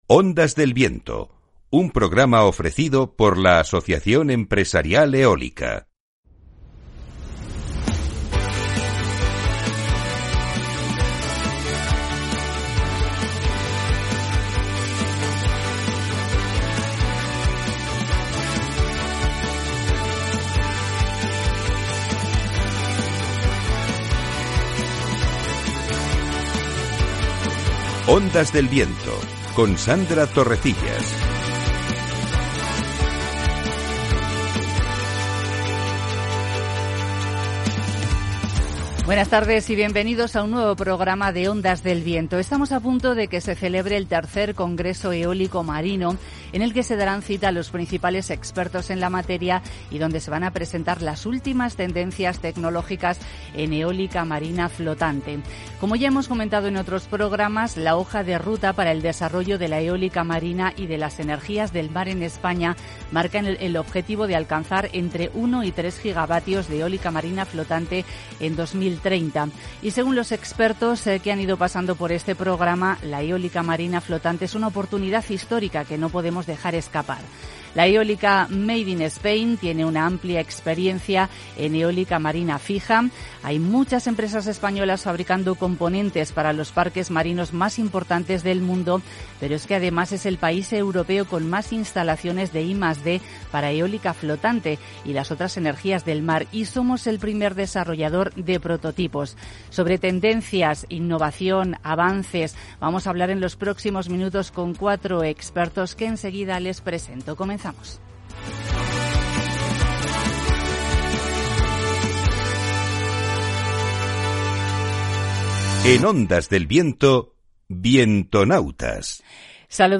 Nuestros expertos invitados de hoy nos van a contar en Ondas del Viento las últimas tendencias tecnológicas en eólica marina: